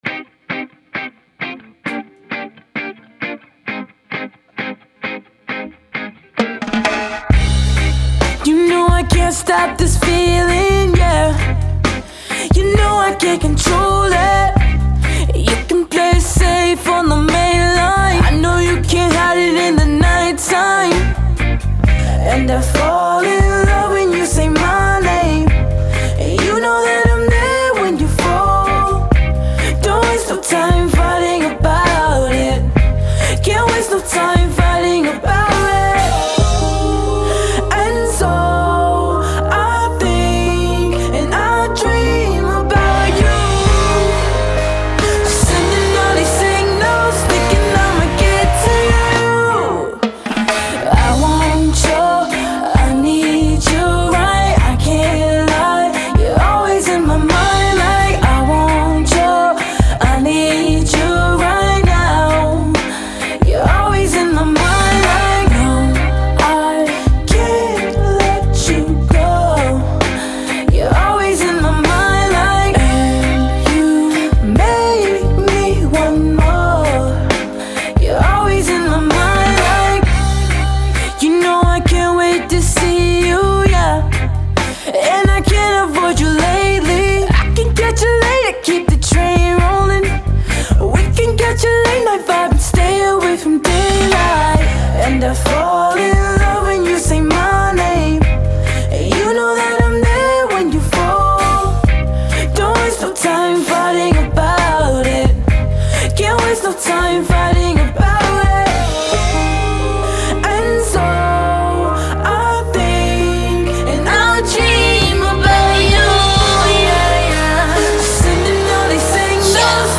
Saving Forever Exclusive Interview